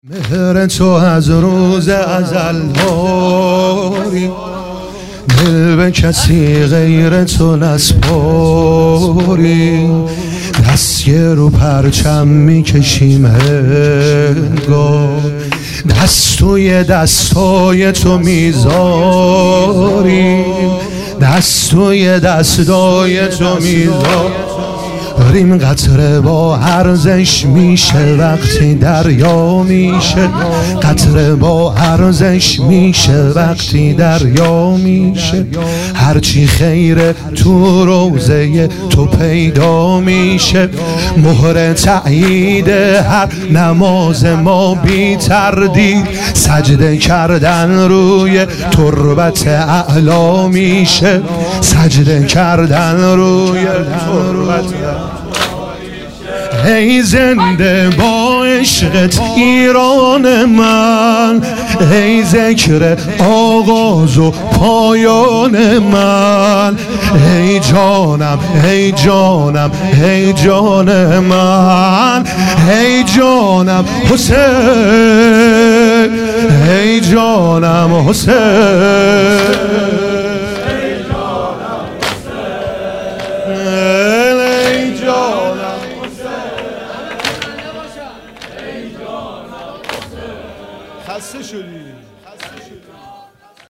عنوان شب سیزدهم صفر ۱۳۹۹